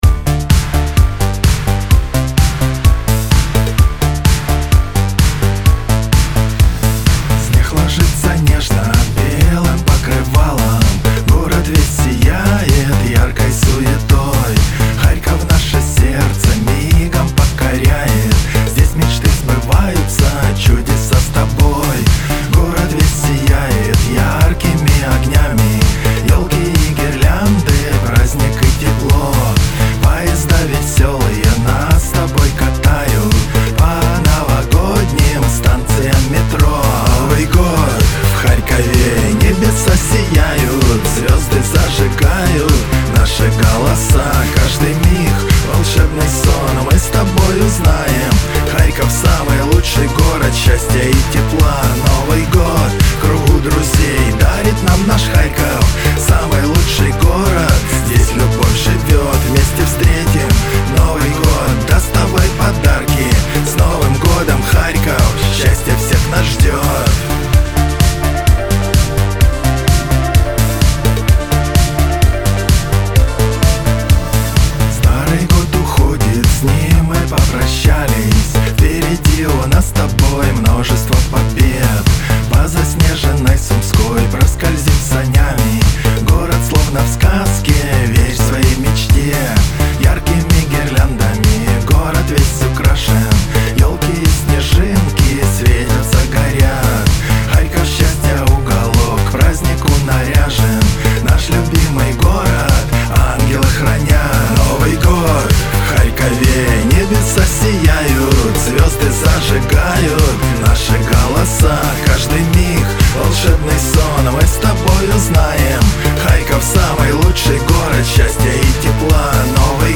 (первоначальная disco-polo версия)